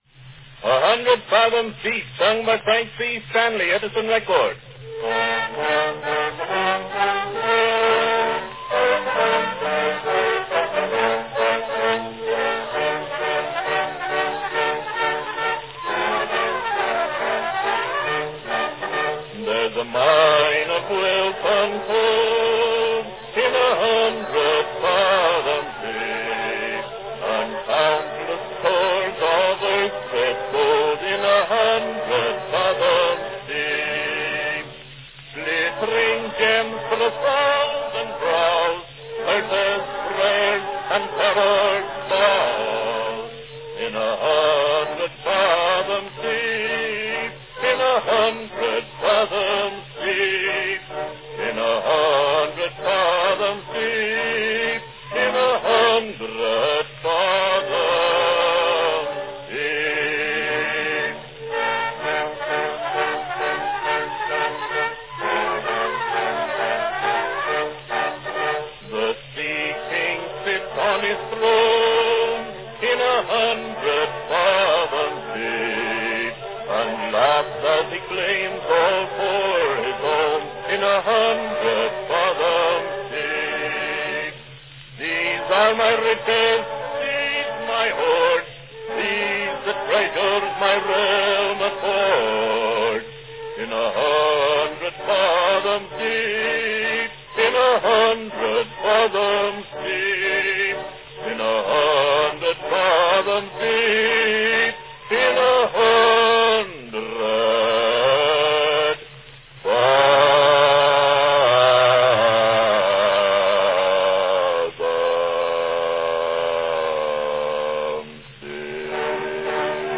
How low can he go? – Listen and find out!
Category Baritone